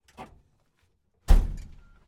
Pole Position - Volvo 142 Rally car